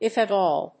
アクセントif at áll